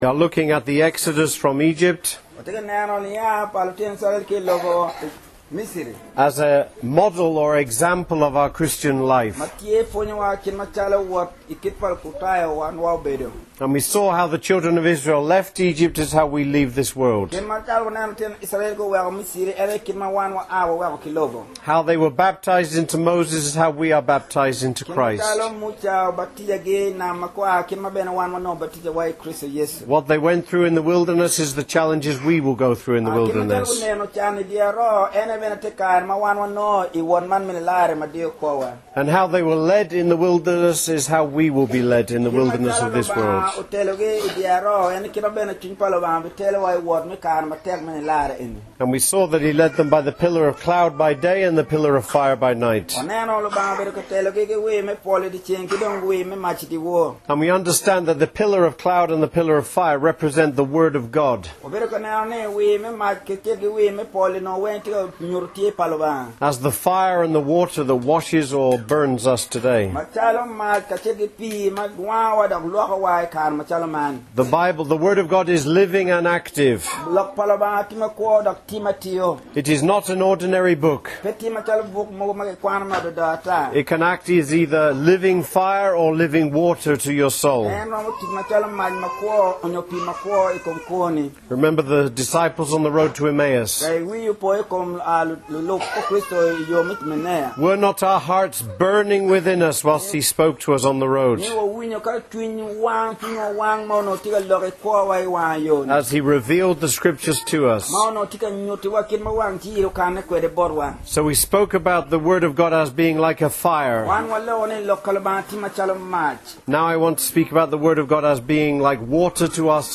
What does the pillar of cloud in the wilderness represent? How the Lord guides His people today. Recorded at Amuru, Northern Uganda and translated into the Luo language.